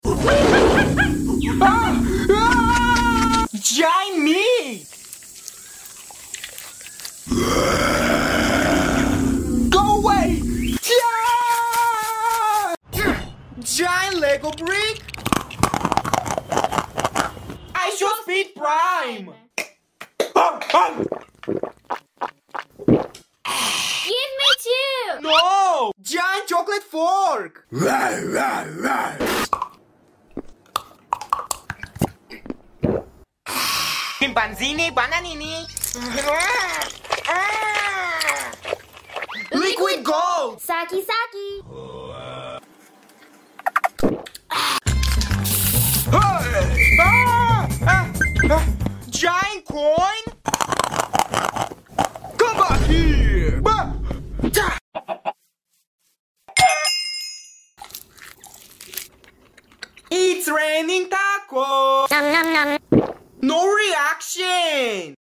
Jungle Food Eating ASMR_ 🤤 sound effects free download